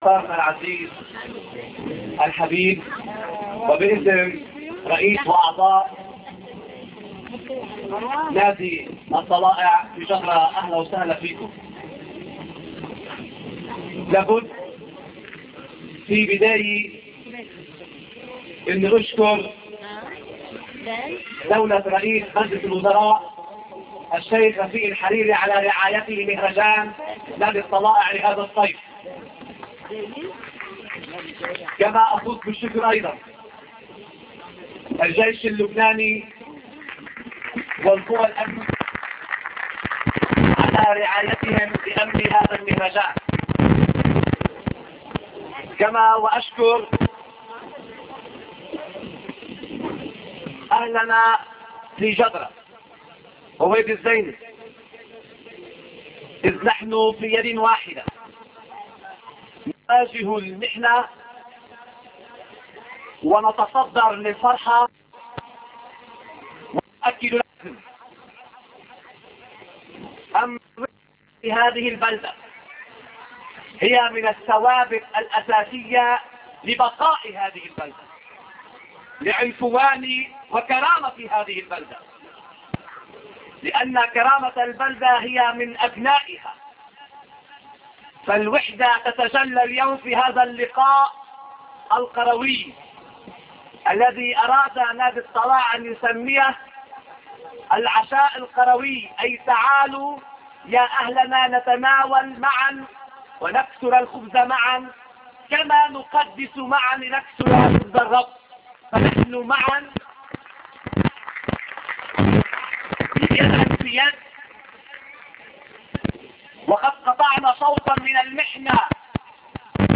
The priest's speech in the annual dinner  n/a